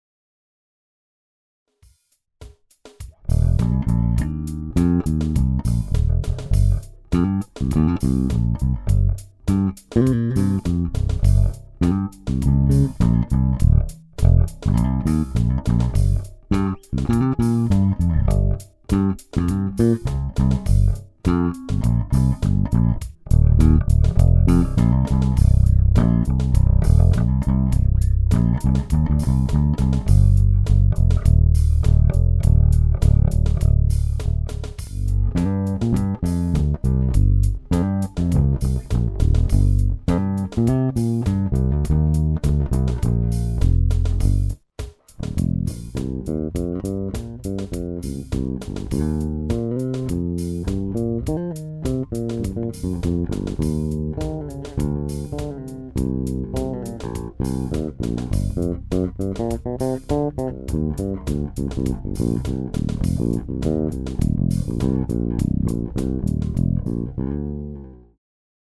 Es ist der älteste Squier, den ich habe, ein 82er. your_browser_is_not_able_to_play_this_audio